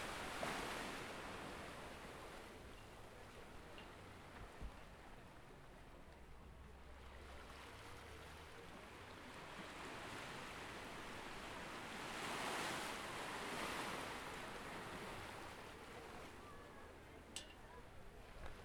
A beach in Jalisco.
TheSea1.wav